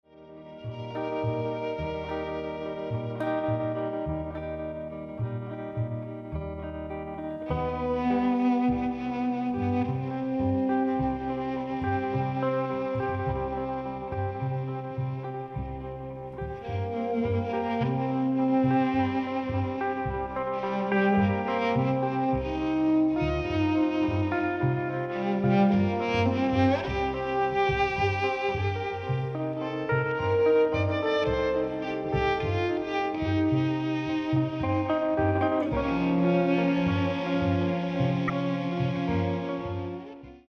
爵士大提琴跨界專輯